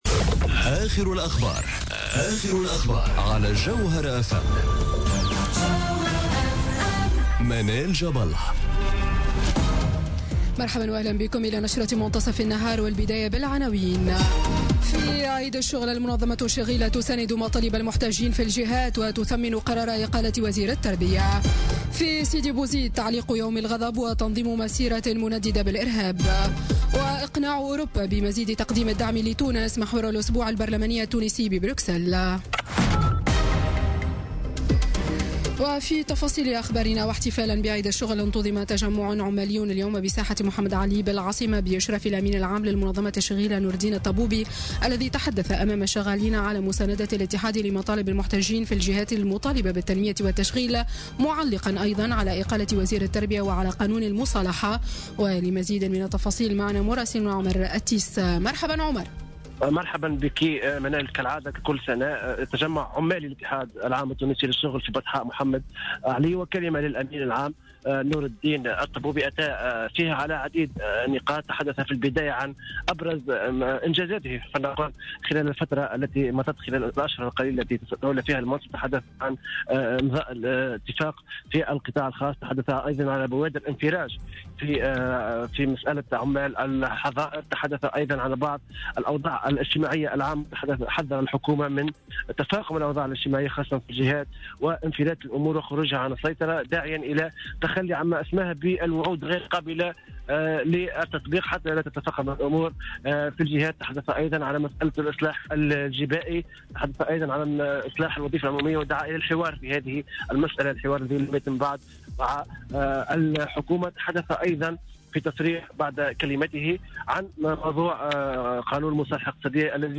نشرة أخبار منتصف النهار ليوم الإثنين غرة ماي 2017